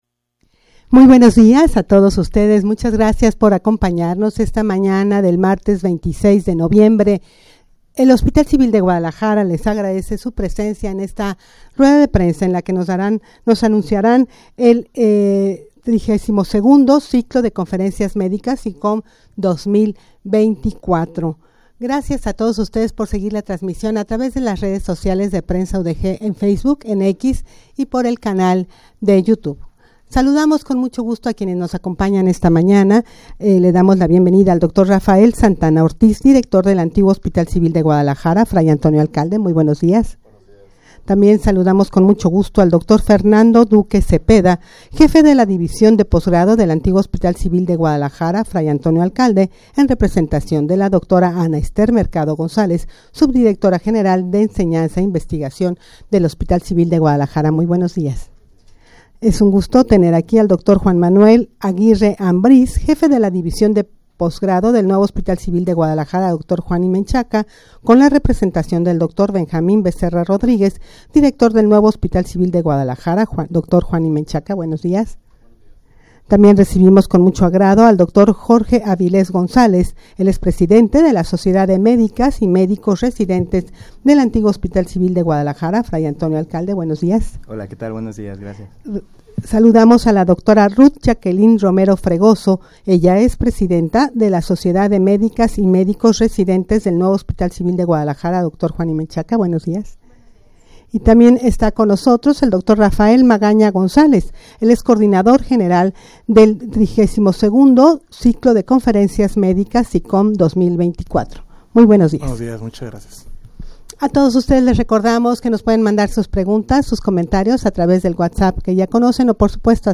Audio de la Rueda de Prensa
rueda-de-prensa-para-anunciar-el-xxxii-ciclo-de-conferencias-medicas-cicom-2024_0.mp3